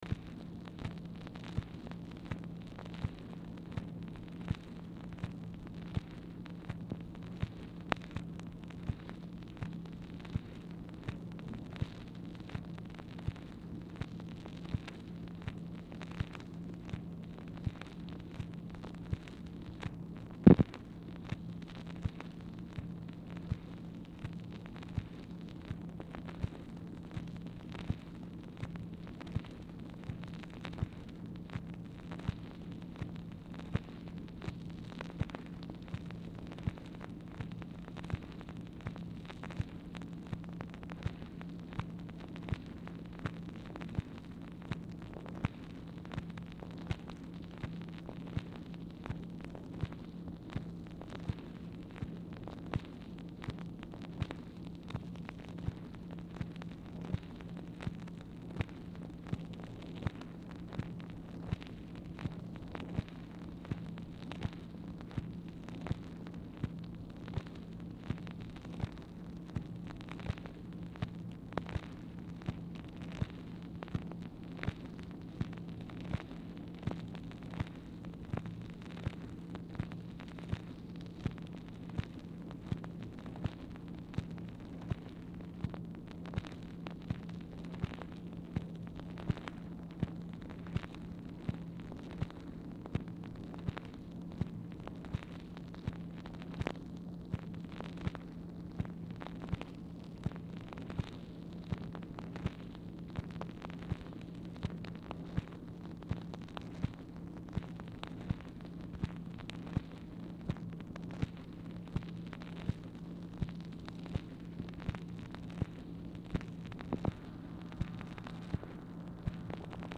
Telephone conversation # 3005, sound recording, MACHINE NOISE, 4/10/1964, time unknown | Discover LBJ
Format Dictation belt
Specific Item Type Telephone conversation